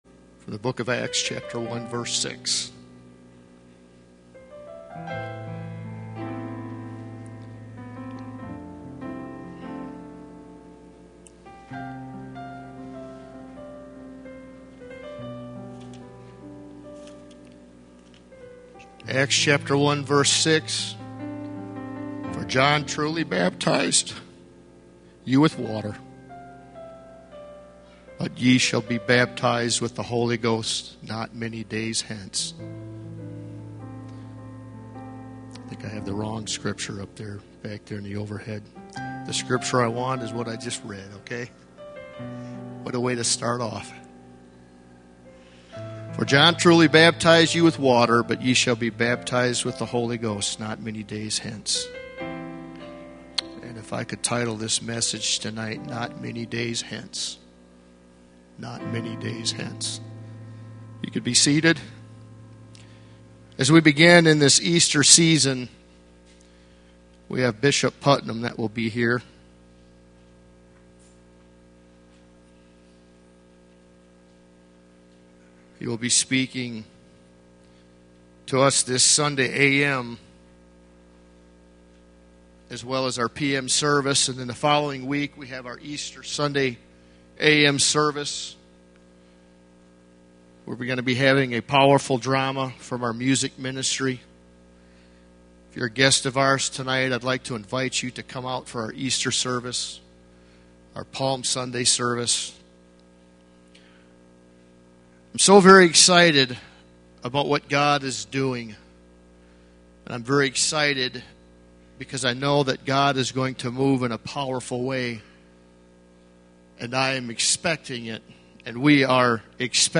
A message from the series "Calvary Gospel Church."